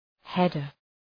{‘hedər}